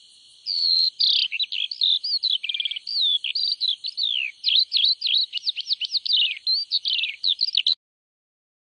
云雀鸟叫声